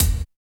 81 IND KIK-L.wav